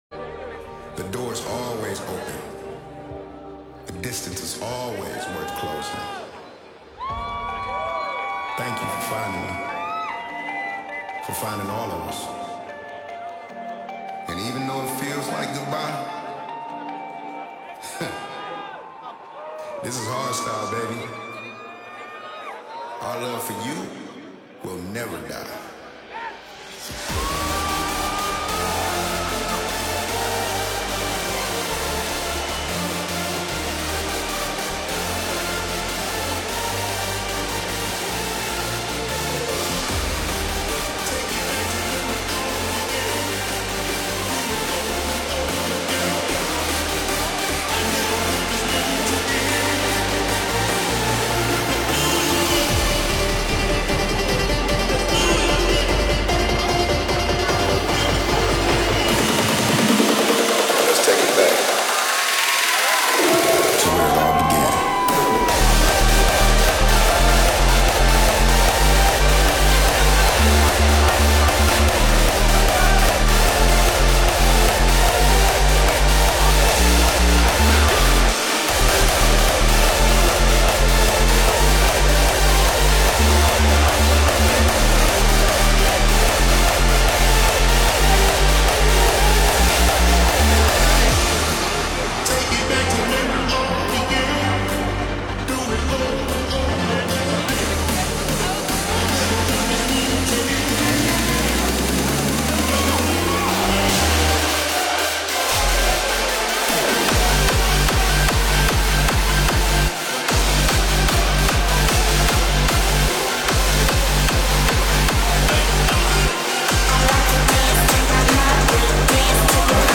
This liveset is embedded on this page from an open RSS feed.